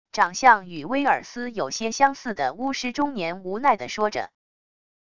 长相与威尔斯有些相似的巫师中年无奈地说着wav音频